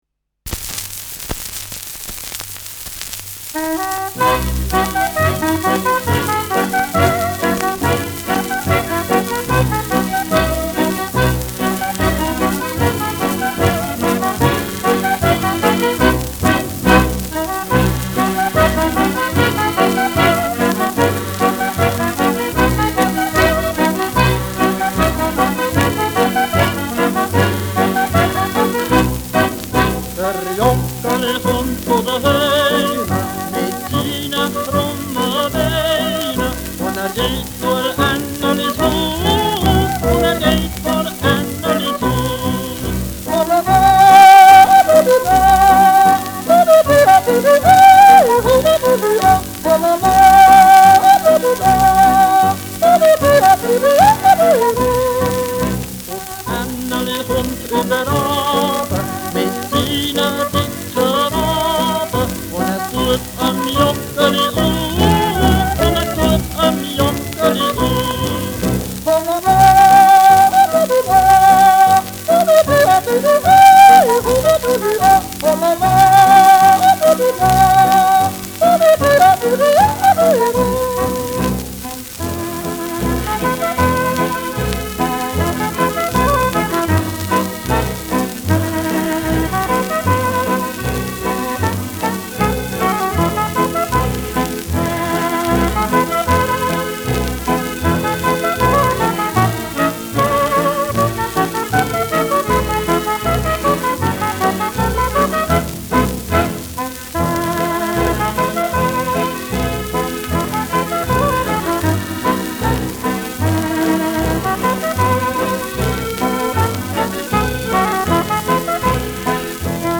Schellackplatte
präsentes Rauschen : präsentes Knistern
[Basel] (Aufnahmeort)
Ländlerkapelle* FVS-00018